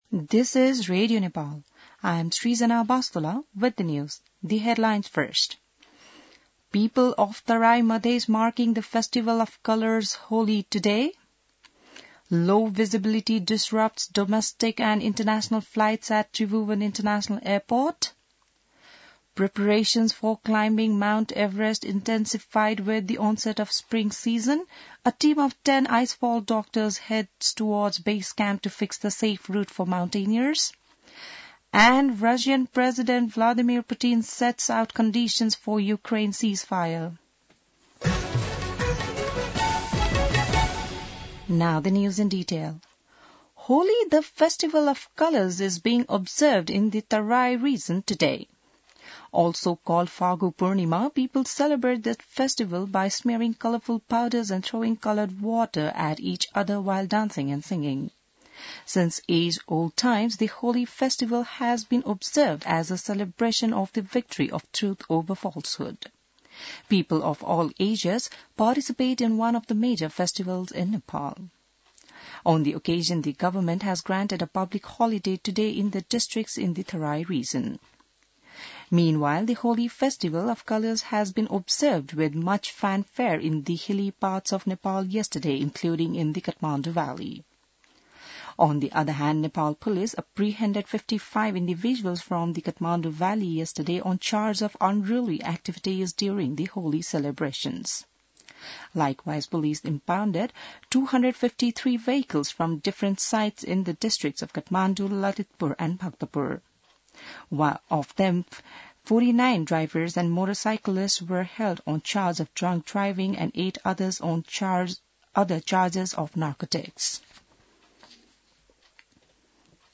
An online outlet of Nepal's national radio broadcaster
बिहान ८ बजेको अङ्ग्रेजी समाचार : १ चैत , २०८१